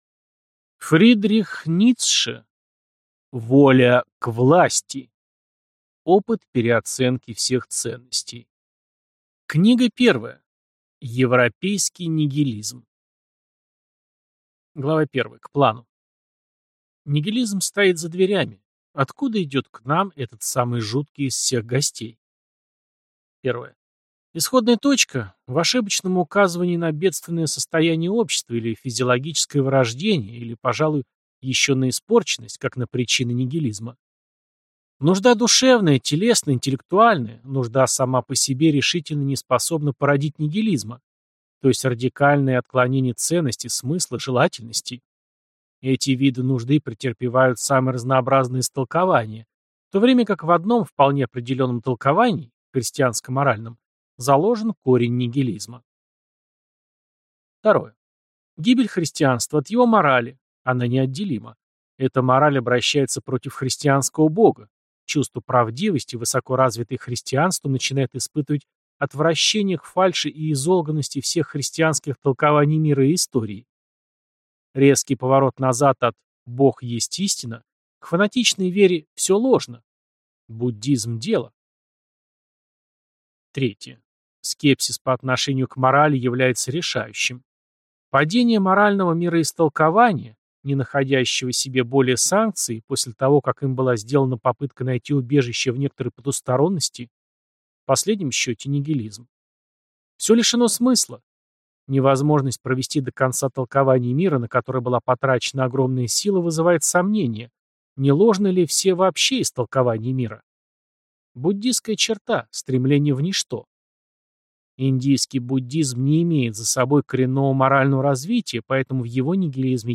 Аудиокнига Воля к власти. Книга первая. Европейский нигилизм | Библиотека аудиокниг